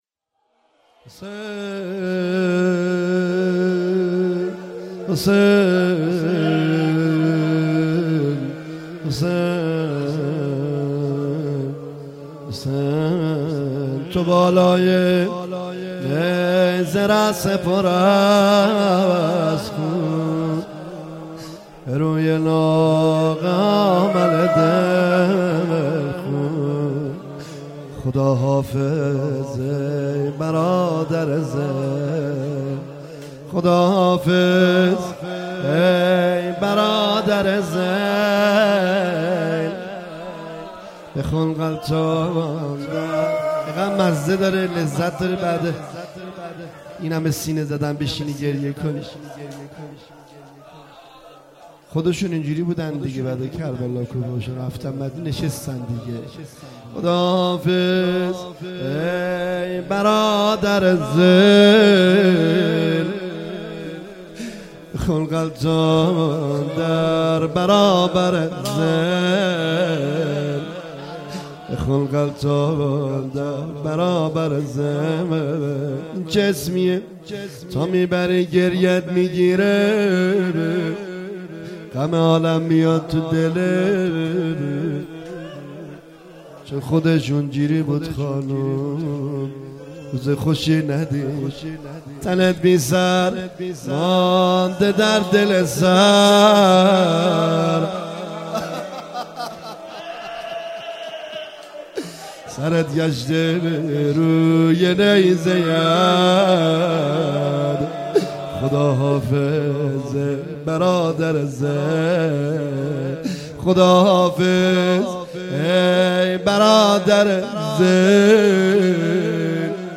14تیر98-شور-روضه پایانی